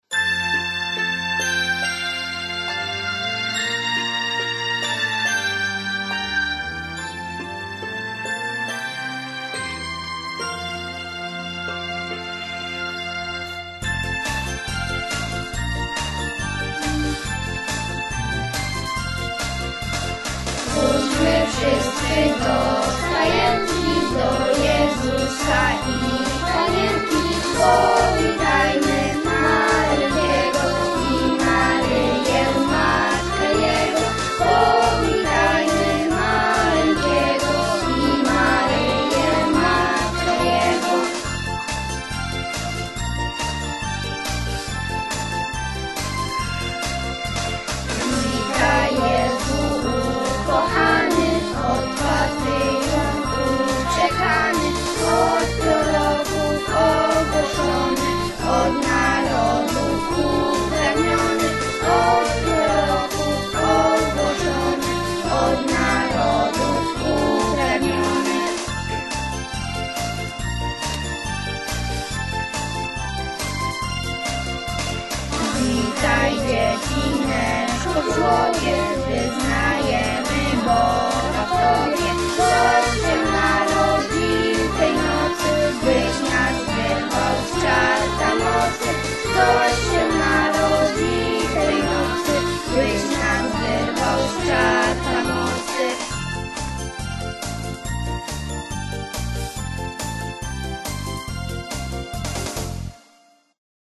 Czas oczekiwania na święta klasy wypełniły wspólnym kolędowaniem.
Kolęda 5c